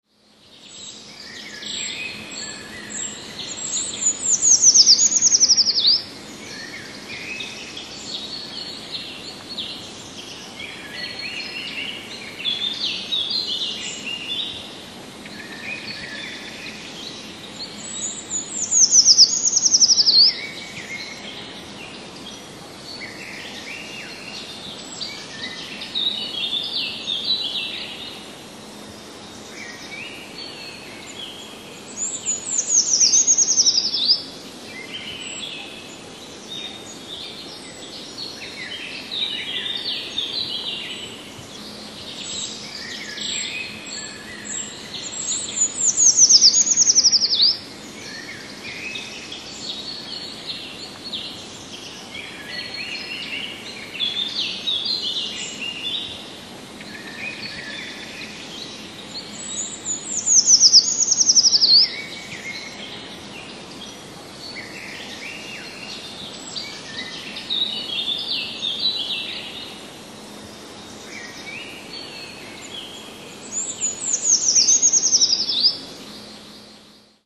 Przykłady śpiewu pełzaczy pochodzą z moich własnych nagrań.
Pełzacz leśny - Certhia familiaris
W marcu samiec zaczyna śpiewać i zajmuje swoje terytorium lęgowe.
Oto przykład śpiewu pełzacza leśnego.